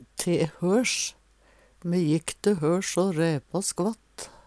te høsj - Numedalsmål (en-US)